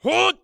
ZS蓄力4.wav
ZS蓄力4.wav 0:00.00 0:00.44 ZS蓄力4.wav WAV · 38 KB · 單聲道 (1ch) 下载文件 本站所有音效均采用 CC0 授权 ，可免费用于商业与个人项目，无需署名。
人声采集素材/男3战士型/ZS蓄力4.wav